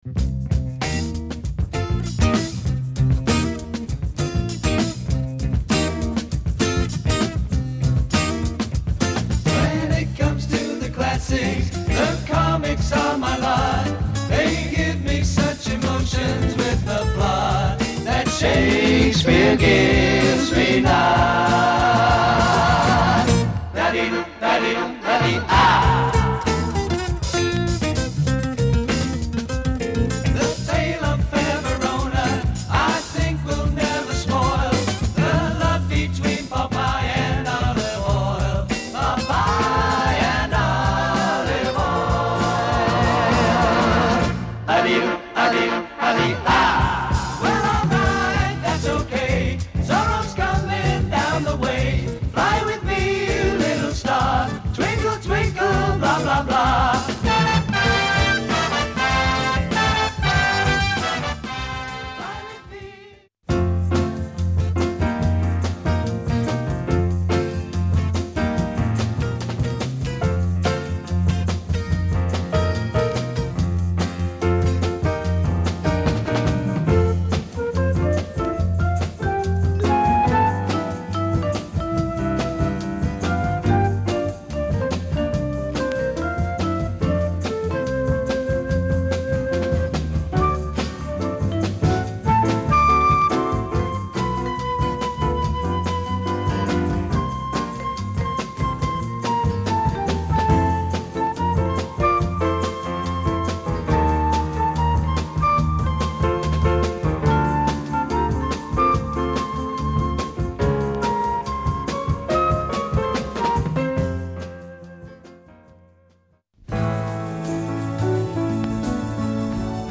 Italian soundtrack